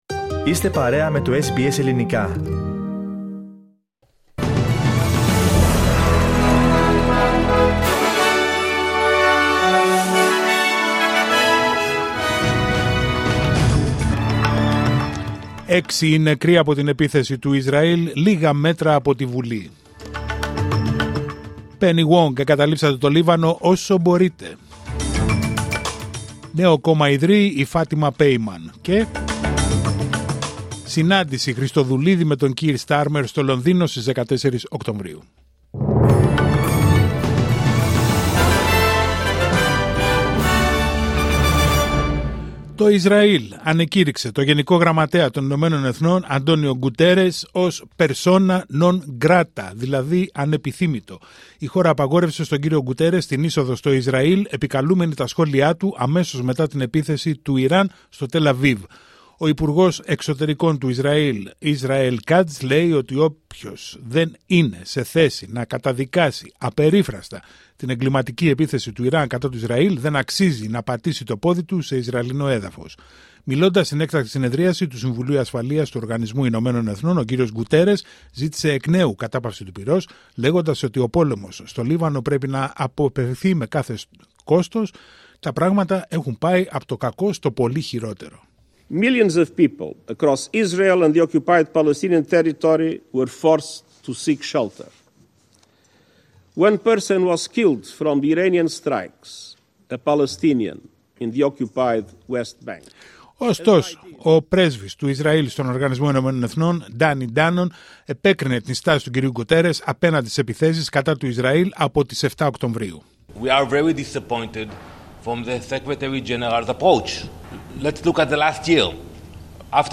Δελτίο ειδήσεων Πέμπτη 3 Οκτωβρίου 2024